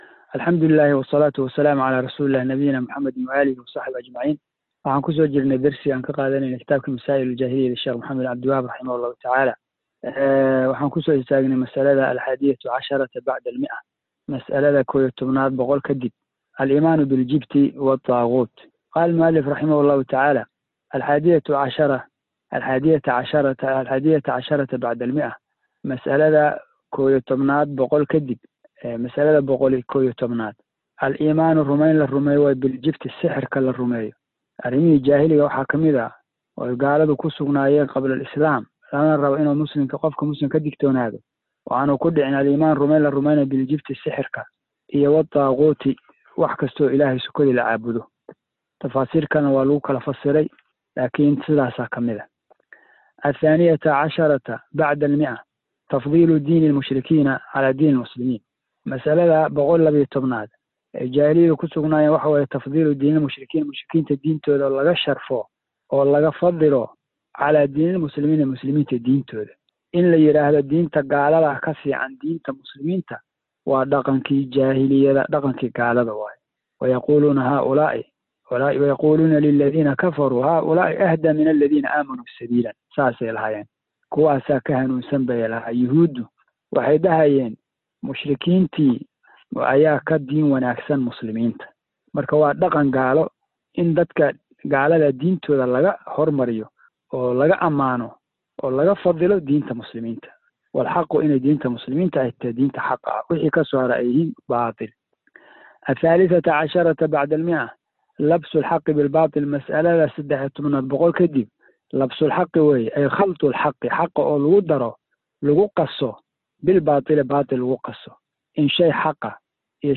Sharrax Kooban Ee Kitaabka Masaa’ilul Jaahiliyyah - Darsiga 7aad - Manhaj Online |